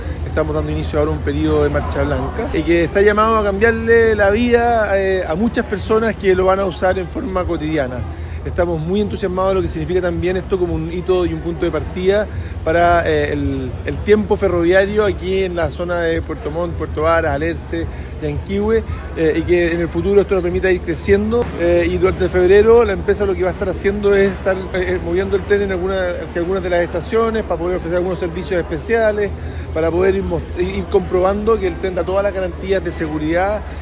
El recorrido finalizó en la estación bimodal del sector La Paloma, con una ceremonia que oficializó el inicio de la marcha blanca. Desde allí, el ministro de Transporte y Telecomunicaciones, Juan Carlos Muñoz, explicó en qué consiste la etapa.